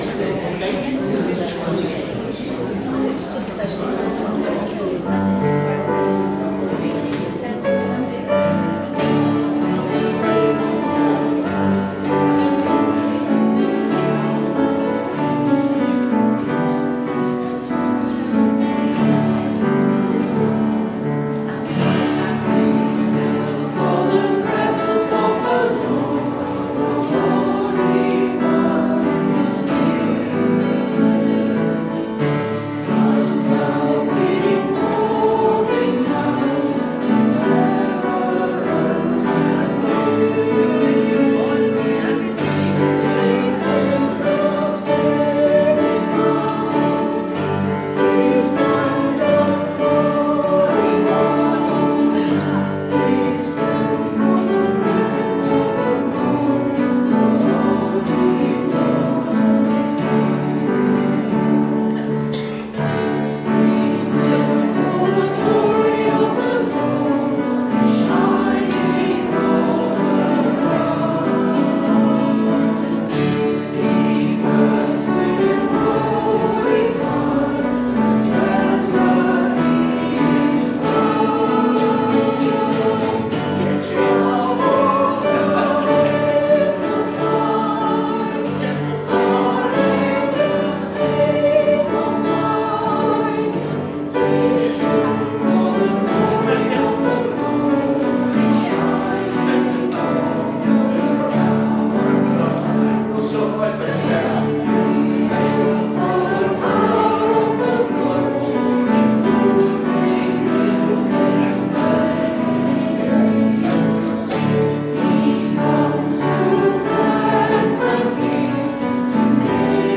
2020-03-15 Morning Worship – Wilmslow Methodist Church
2020-03-15 Morning Worship